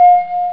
bell2.wav